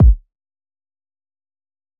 Kick [Famous2].wav